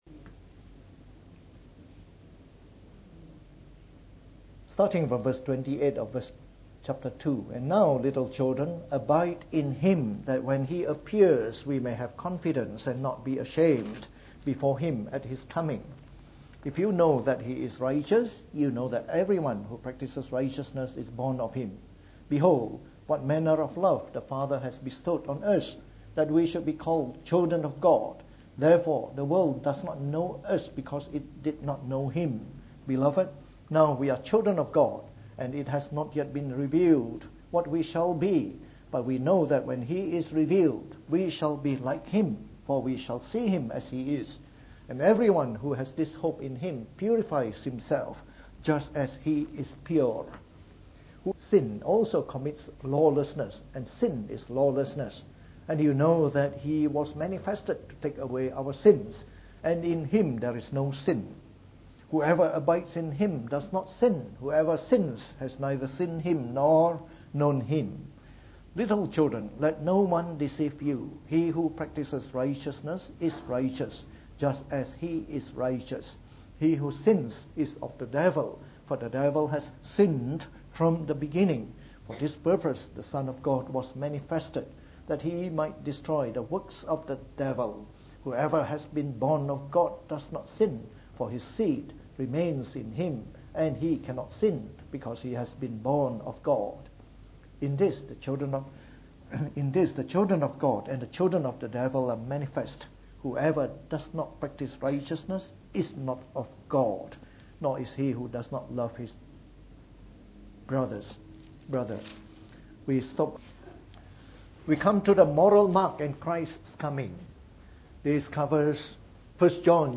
From our series on the Book of 1 John delivered in the Morning Service.